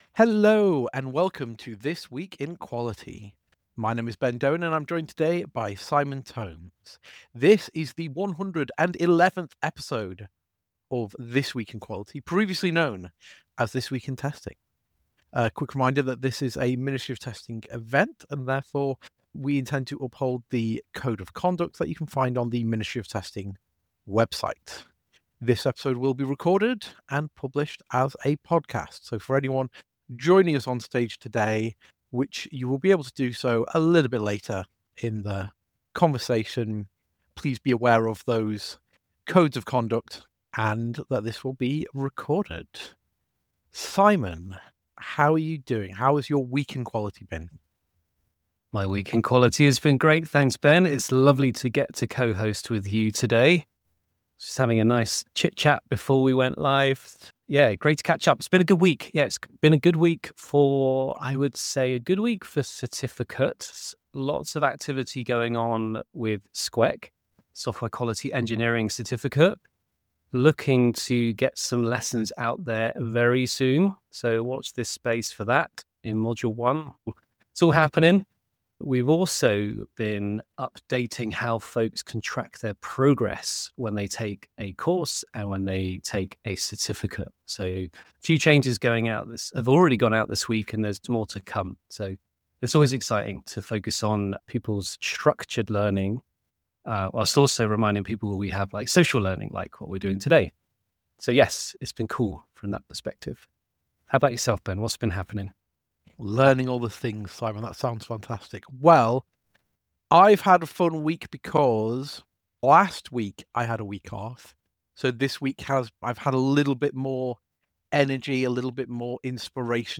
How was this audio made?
Join the live session every Friday or catch up on past episodes wherever you get your podcasts.